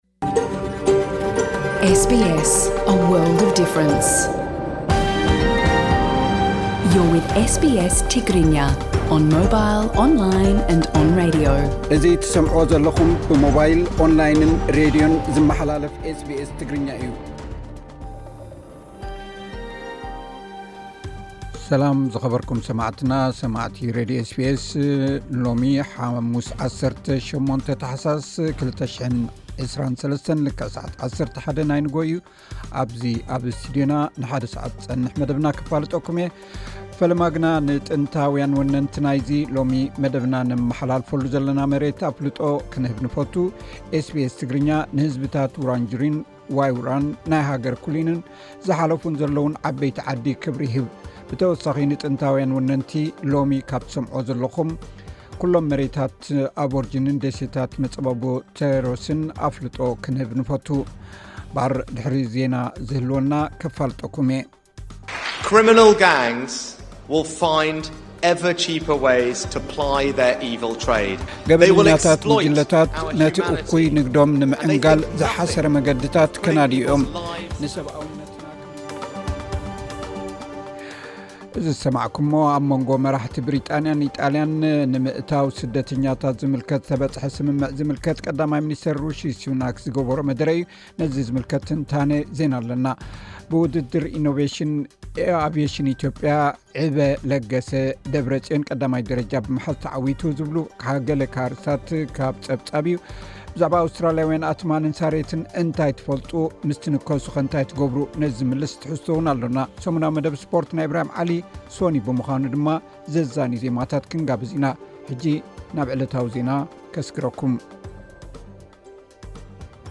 ሶኑይ ብምዃኑ ድማ ዘዛንዩ ዜማታት ክንጋብዝ ኢና።